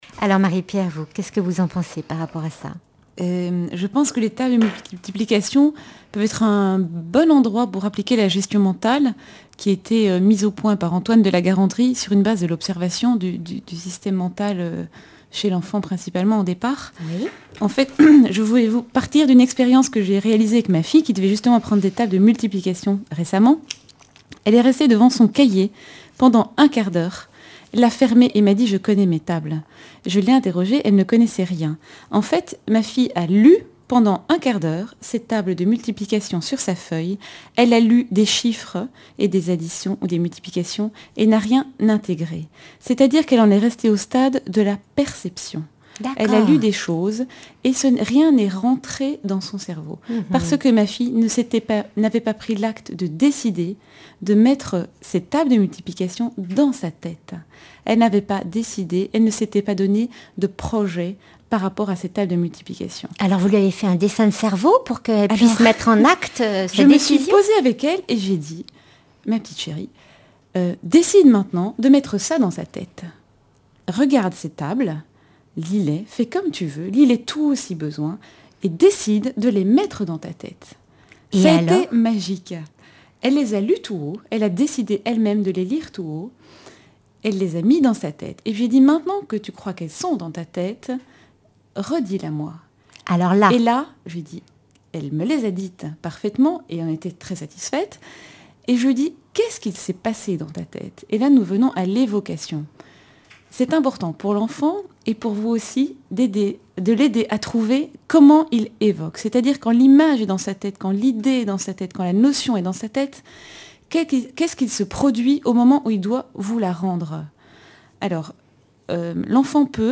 Emissions de la radio RCF Vendée
Témoignage